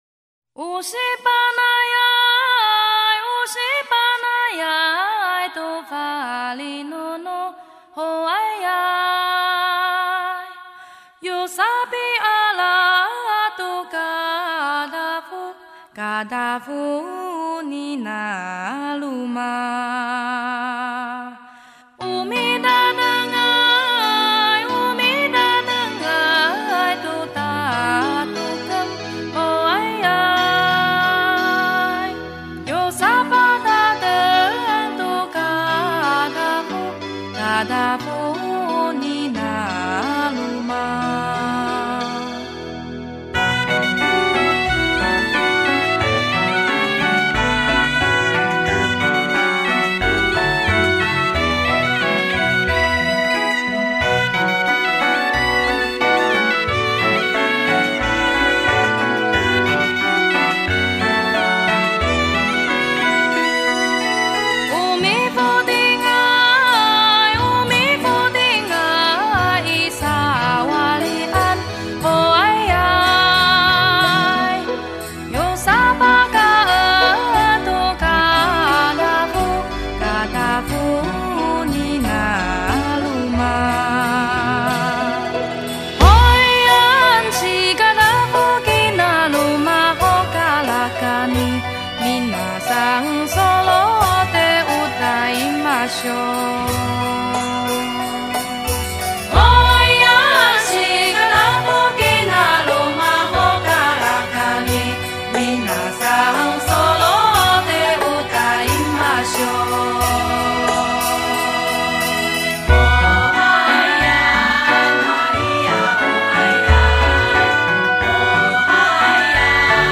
世界音乐